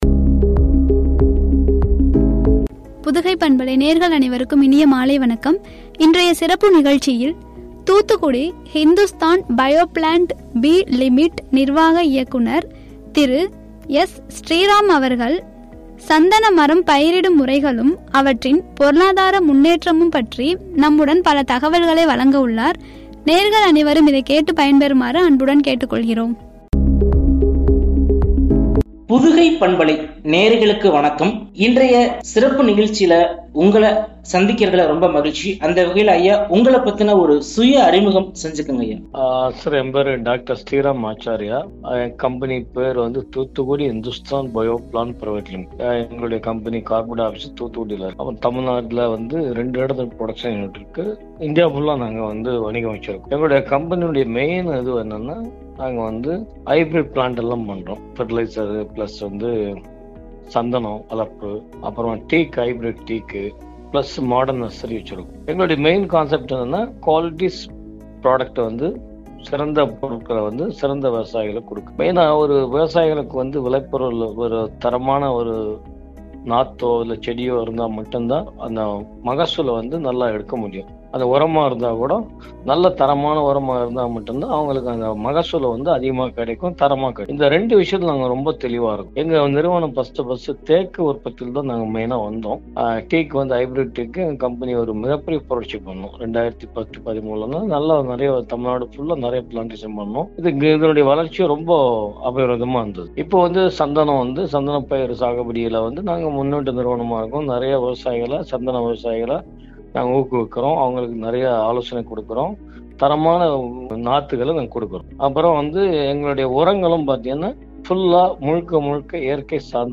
பொருளாதாரம் முன்னேற்றமும்” என்ற தலைப்பில் வழங்கிய உரை.